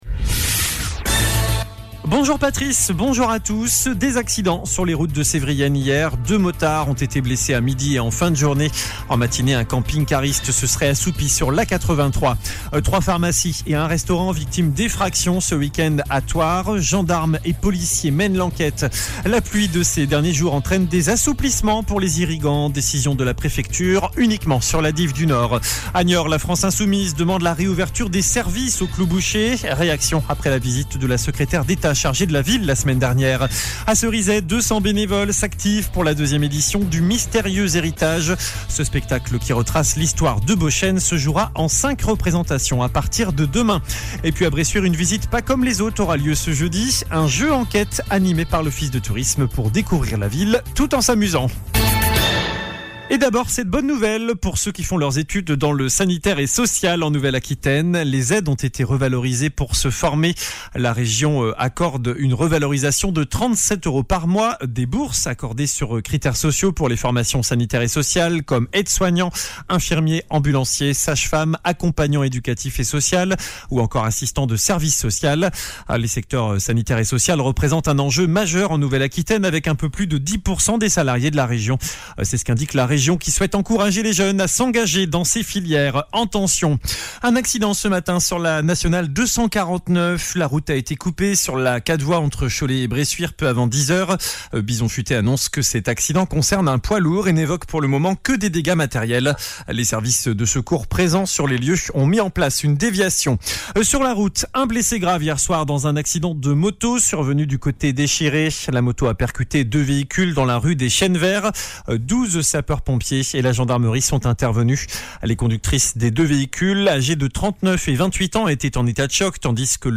JOURNAL DU MERCREDI 09 AOÛT ( MIDI )